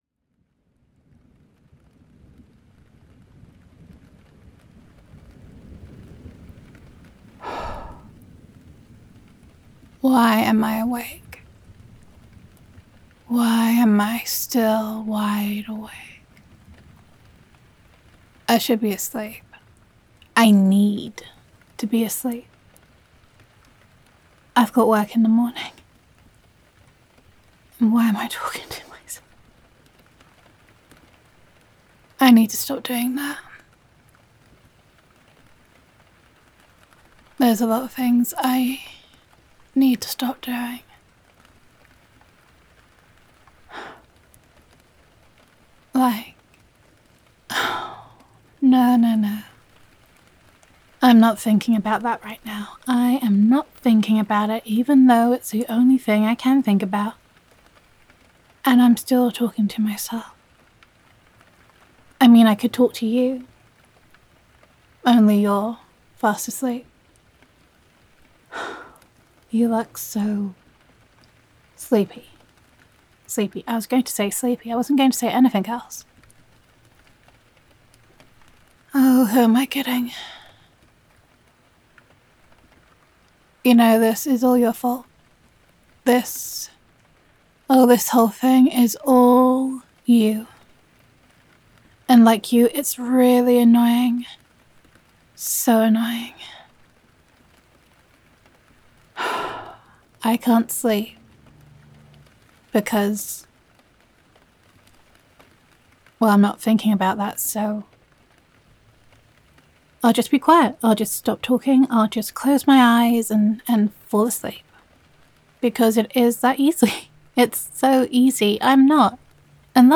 Character Type: Insomniac Girlfriend
Downloads Download [F4A] Why Am I Still Wide Awake [Girlfriend Roleplay].mp3 Content I’m lying here, trying to sleep, but all I can think about is how much you annoy me… and how much I love you.
Emotional Tone or Mood: Sweet, Confessional, Light-Hearted